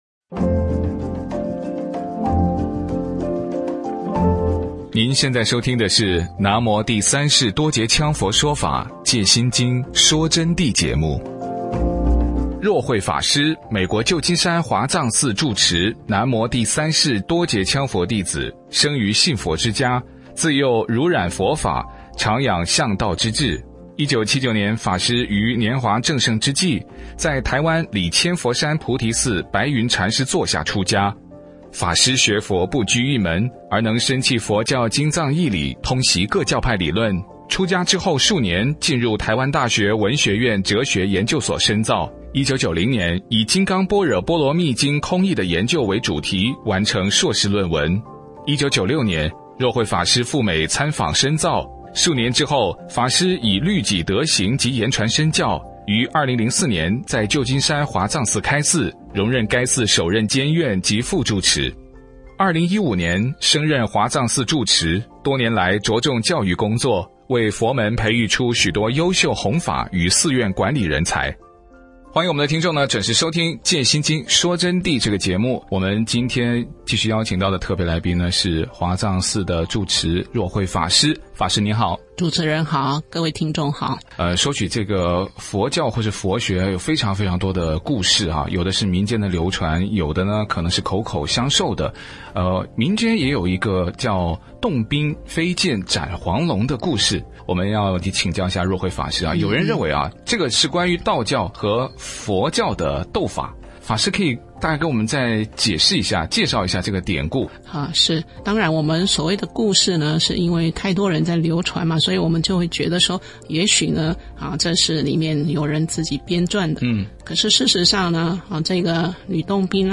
佛弟子访谈（十五）学佛有要求吗？什么是皈依？佛法僧三宝的含义是什么？